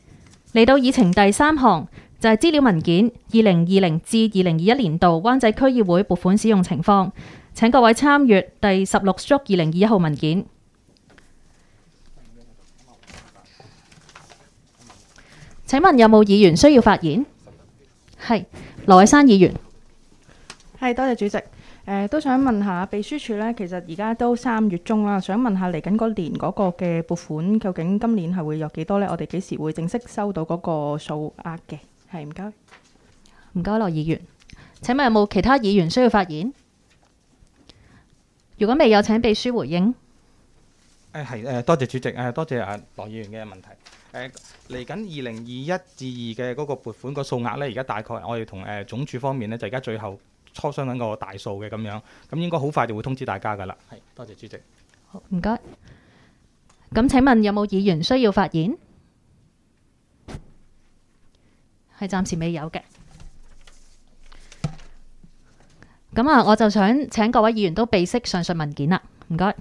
区议会大会的录音记录
湾仔区议会第十次会议
湾仔民政事务处区议会会议室